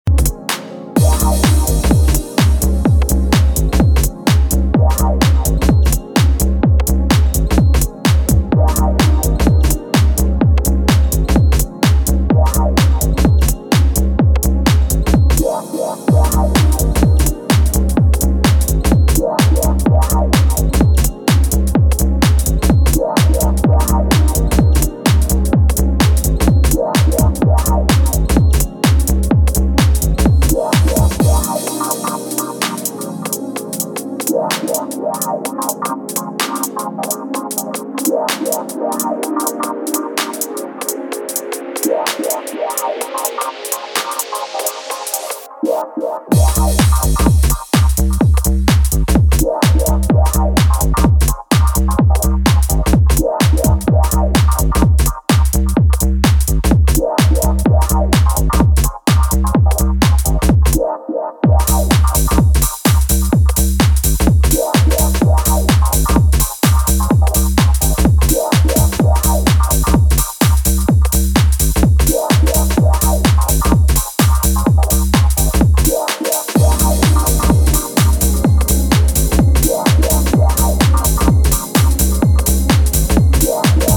fast pace, bumpy bass, cut-to-the-chase -